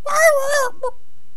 chicken_die2.wav